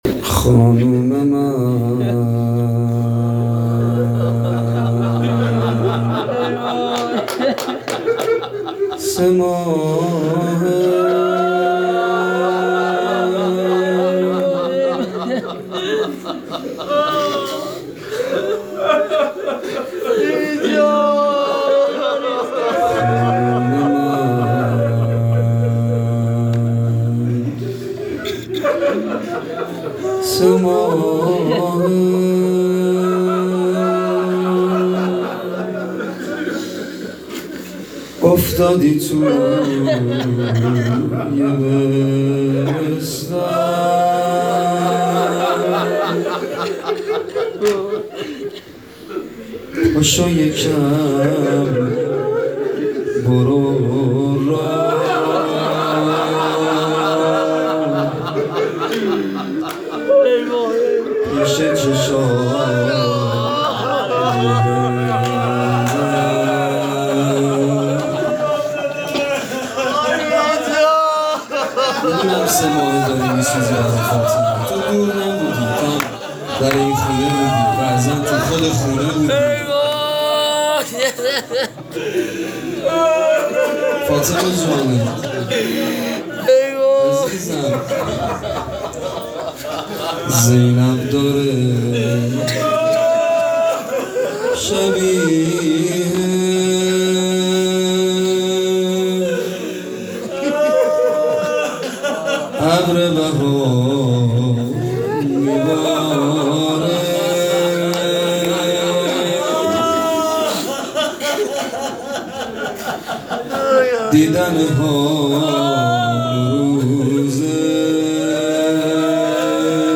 فاطمیه دوم ۹۷